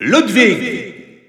Announcer pronouncing Ludwig in French.
Ludwig_French_Announcer_SSBU.wav